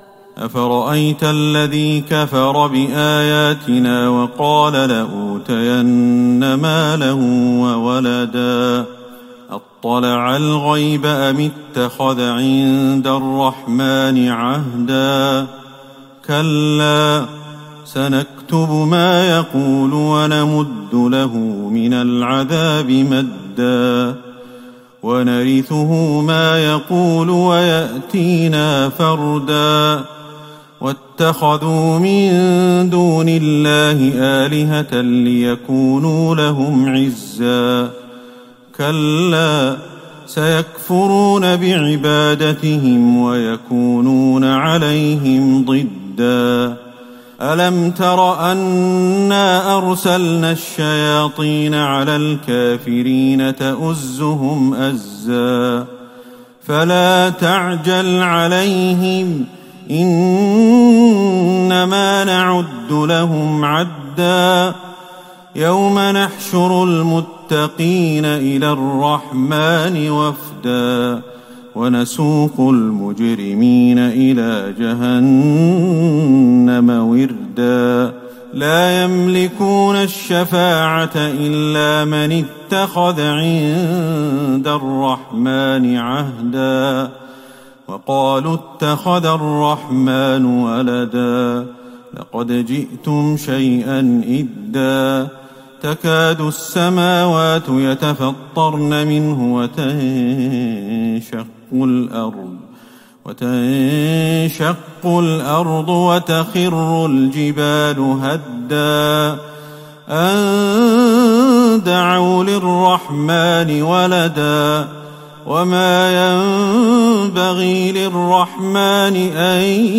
تراويح ليلة ٢١ رمضان ١٤٤١هـ من سورة مريم { ٧٧-٩٧ } وطه { ١-٨٢ } > تراويح الحرم النبوي عام 1441 🕌 > التراويح - تلاوات الحرمين